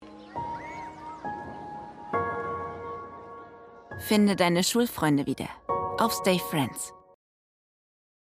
TVC & Online: Stayfriends
TVC_Stayfriends_Claim.mp3